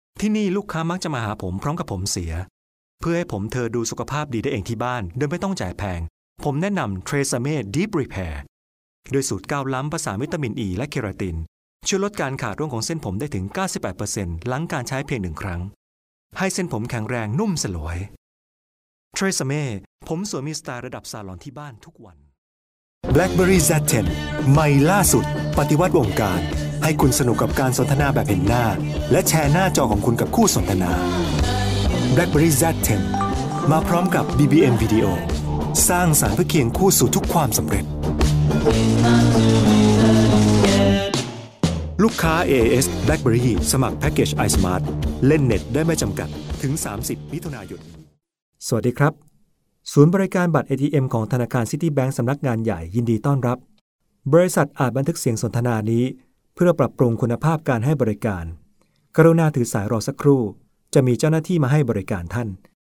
Professionelle Sprecher und Sprecherinnen
Männlich